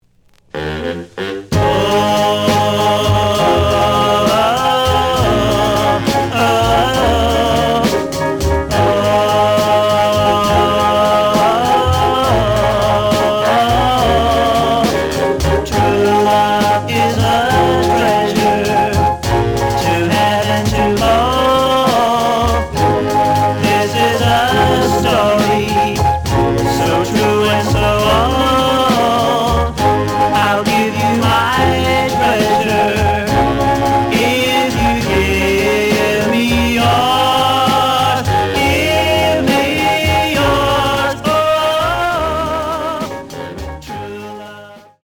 The audio sample is recorded from the actual item.
●Genre: Rhythm And Blues / Rock 'n' Roll
B side is slight cracking sound.)